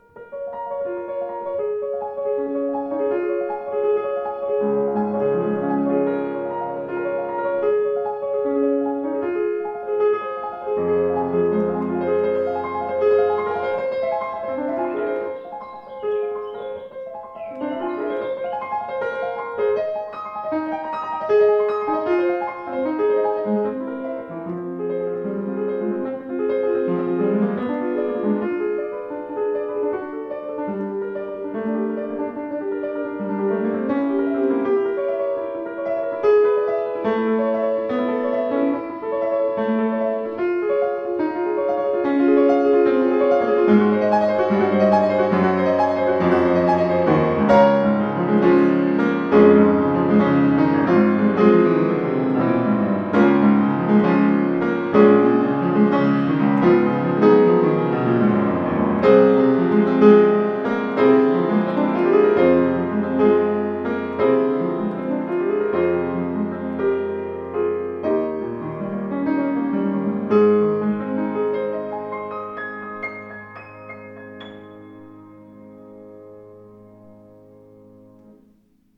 Dämpfungspedal (Moderator), großes Tonvolumen dank neuer Konstruktion mit großzügig ausgelegtem Resonanzboden aus ausgesuchter Bergfichte.
Klaviere